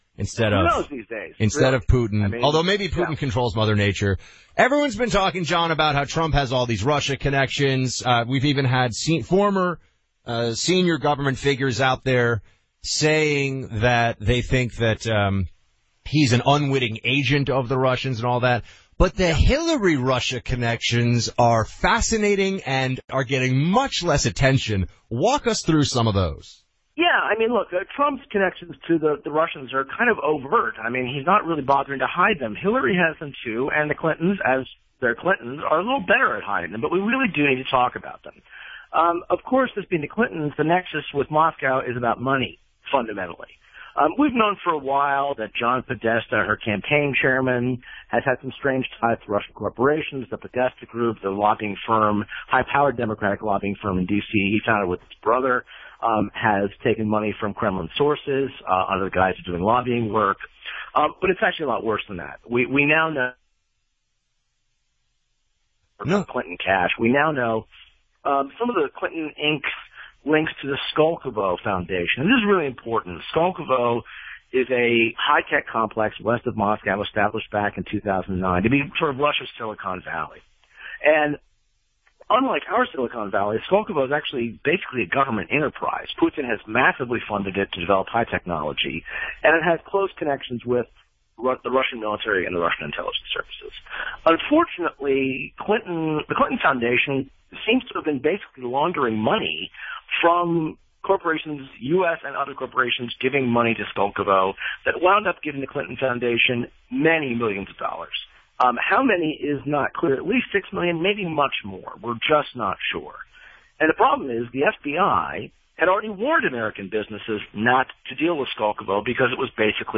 discuss the GAI report on Russia’s Silicon Valley and the dangers it outlines to America’s national security on the Rush Limbaugh Show.